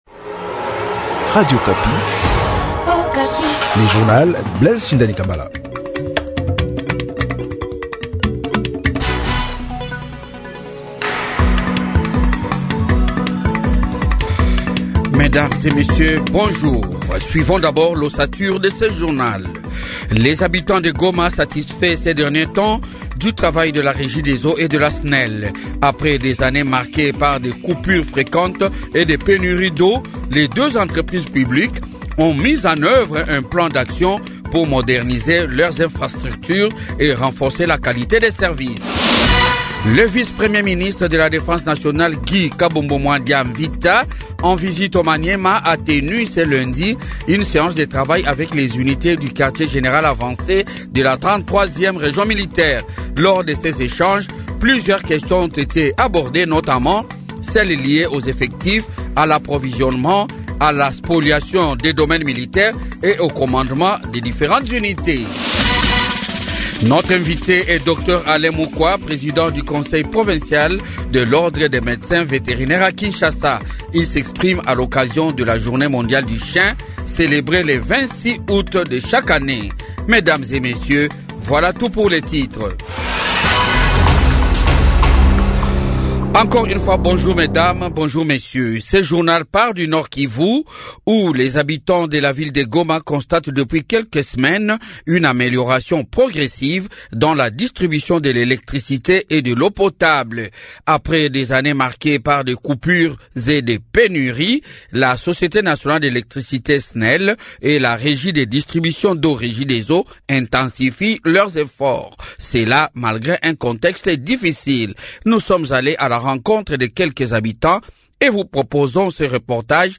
Journal Francais matin 8H